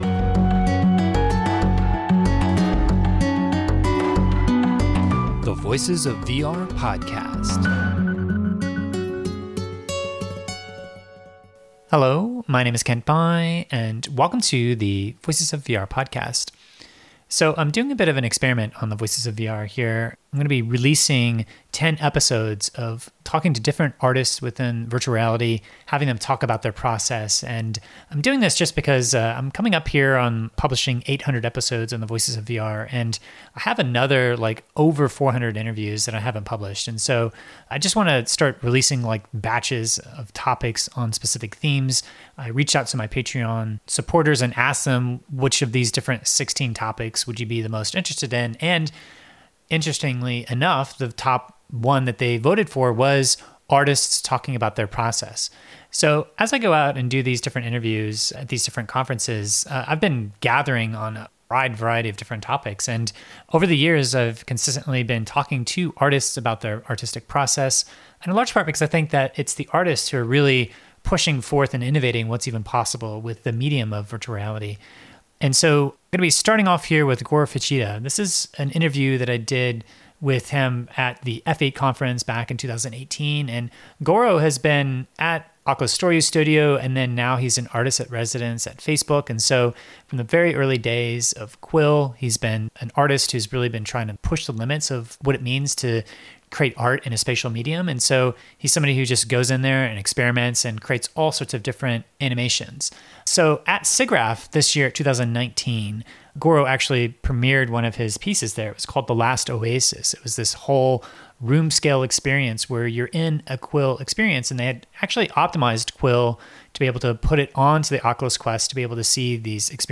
I had a chance to talk to him at F8 in 2018 where he told me about his journey into creating spatial art and experiential stories with Oculus Quill as his main tool of choice.